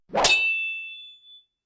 Melee Weapon Attack 2.wav